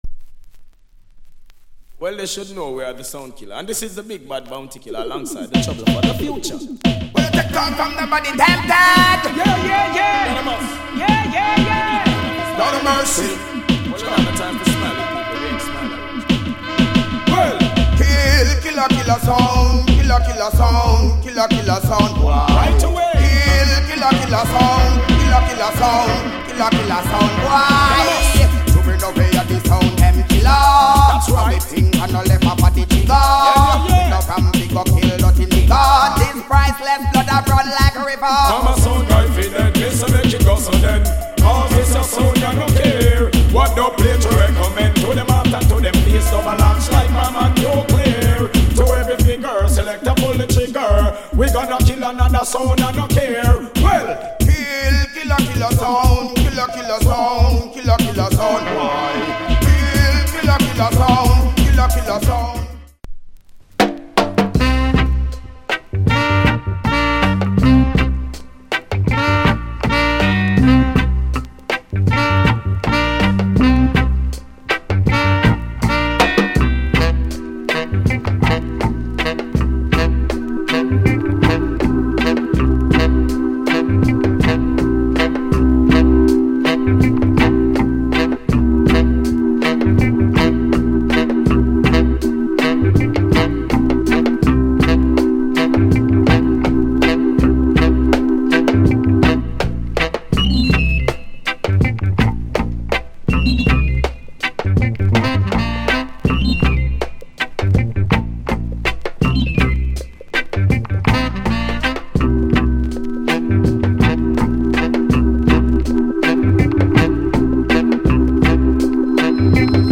Genre ReggaeAfter90s / [A] Male DJ Combi [B] Version